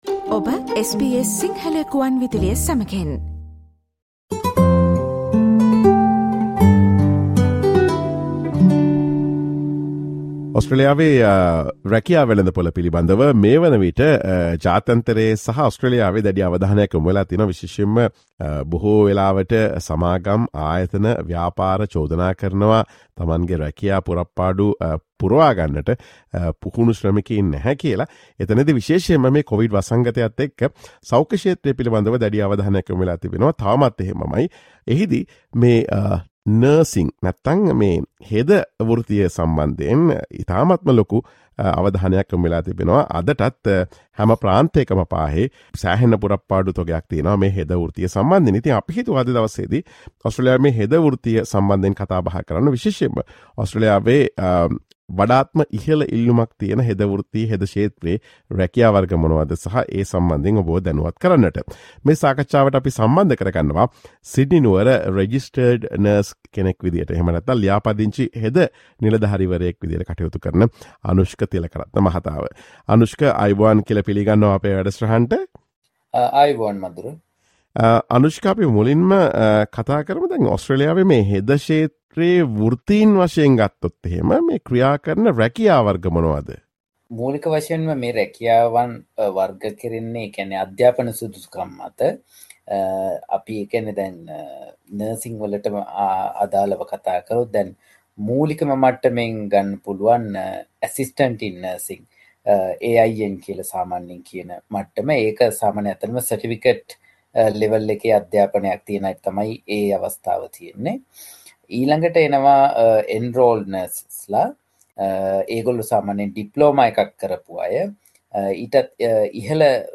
ඔස්ට්‍රේලියාවේ හෙද ක්ෂේත්‍රයේ වඩාත්ම ඉල්ලුමක් ඇති රැකියා සහ ඒවායේ වැටුප් පැකේජ පිළිබඳ SBS සිංහල ගුවන් විදුලිය සිදුකළ සාකච්ඡාවට සවන් දෙන්න.